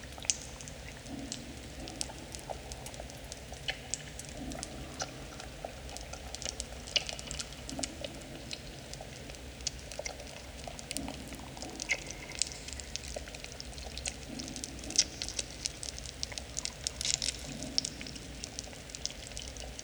cave_ambience_loop_01.wav